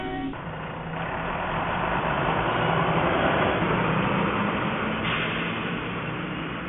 bus.mp3